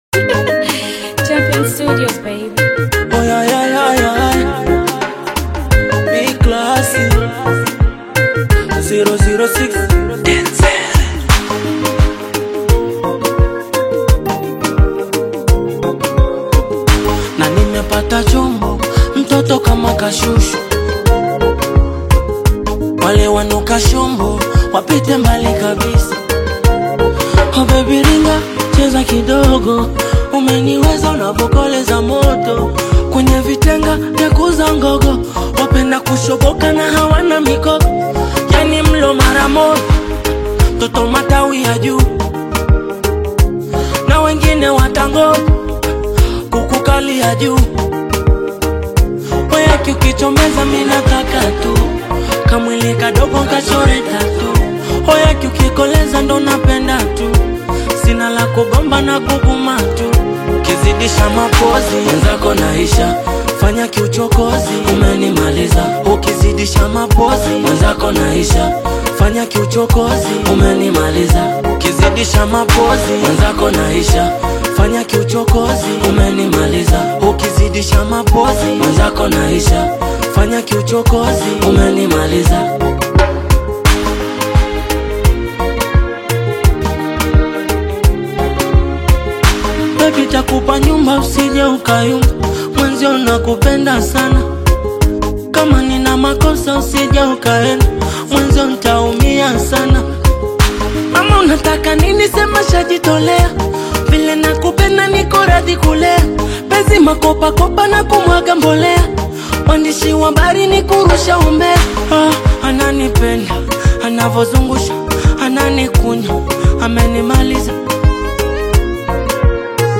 Afro-Pop single